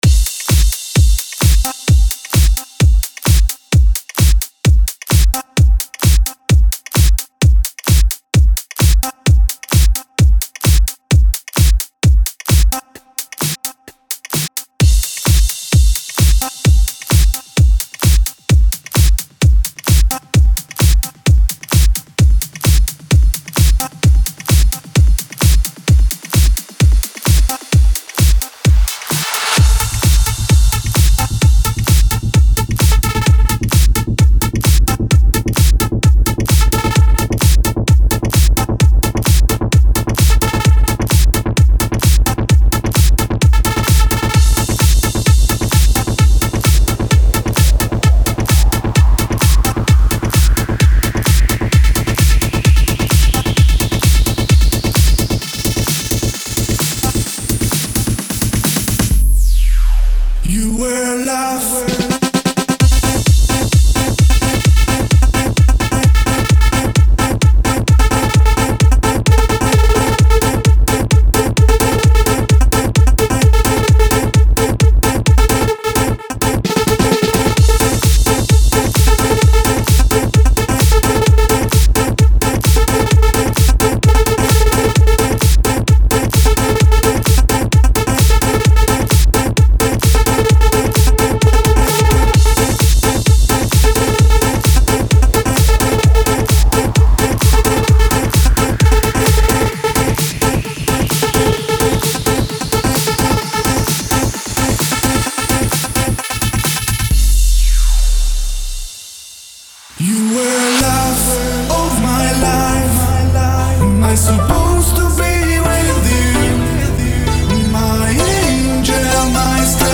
Жанр:Dance